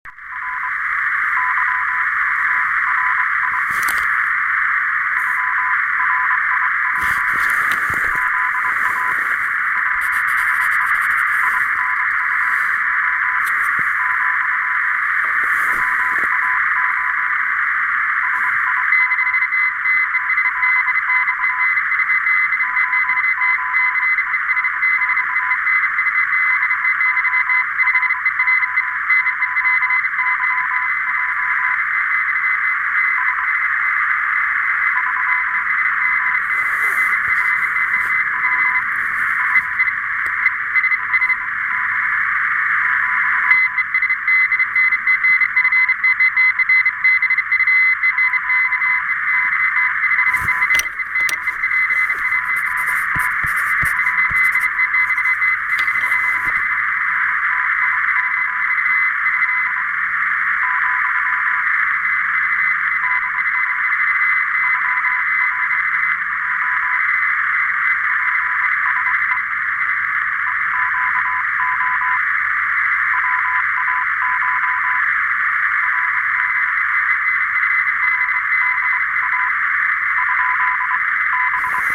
Сегодня была возможность, время было, поработать в эфире QRPP/p и QRP/p.
Как поляк владеет русским! Я даже подумал, что он вообще русский, нет оказалось поляк...